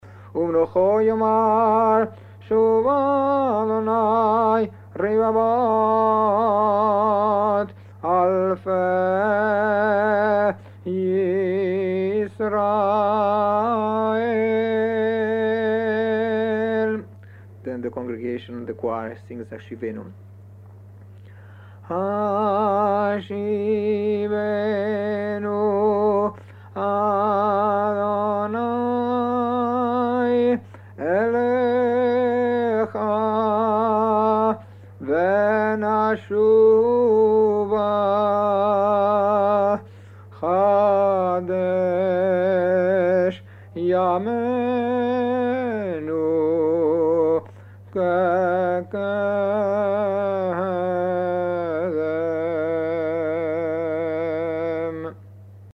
Spanish-Portuguese Tunes
[Western Sephardic]